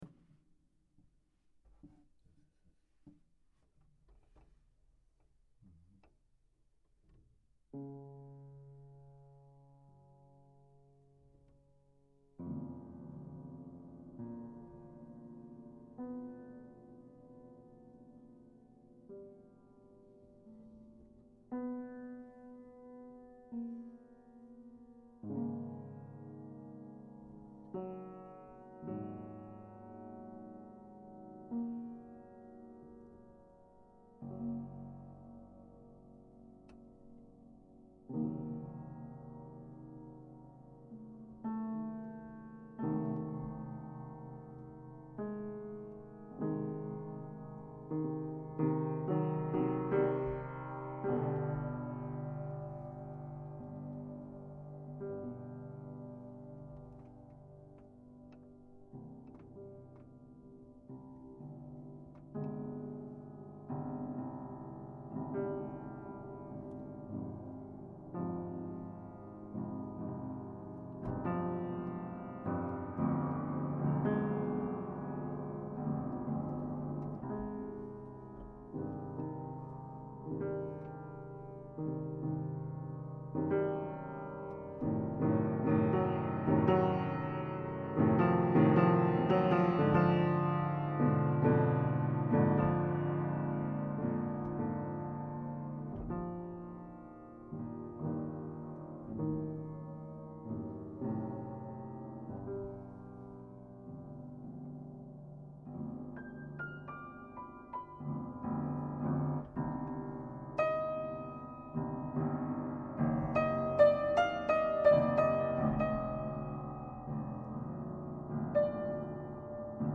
А вчера неожиданно сыгралась фортепианная пьеса, закончив которую сразу понял, что